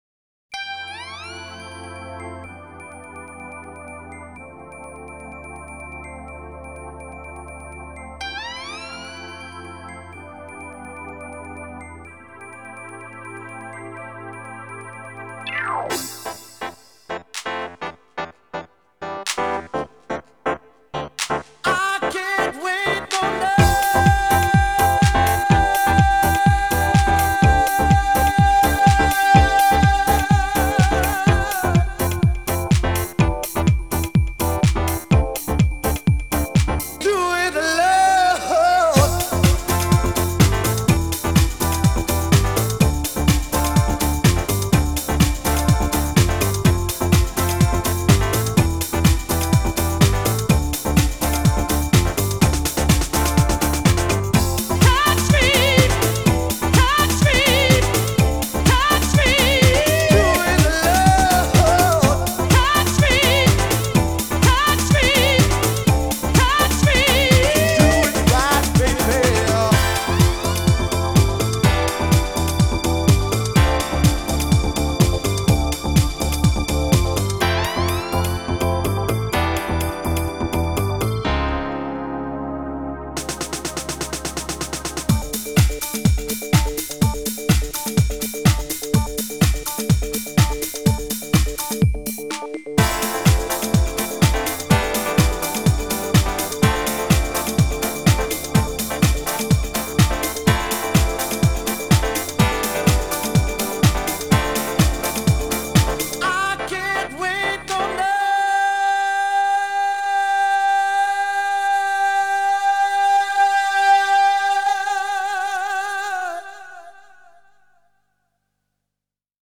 BPM125-250